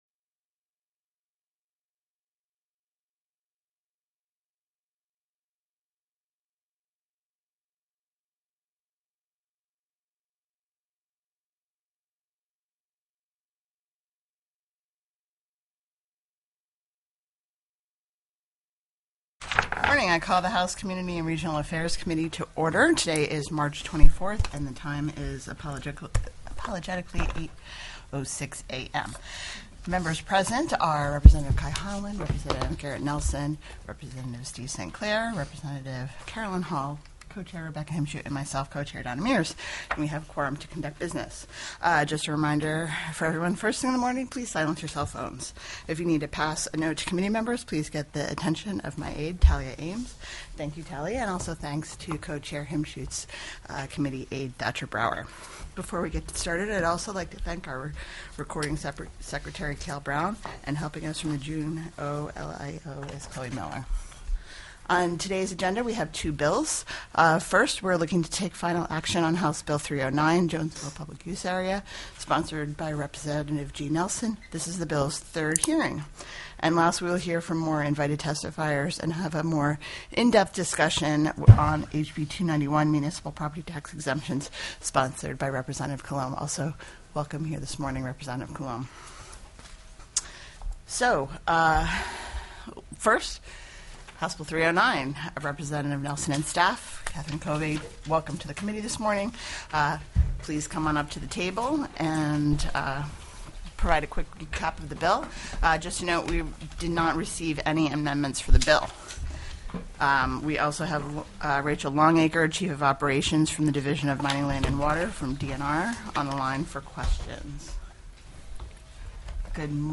The audio recordings are captured by our records offices as the official record of the meeting and will have more accurate timestamps.
HB 309 JONESVILLE PUBLIC USE AREA TELECONFERENCED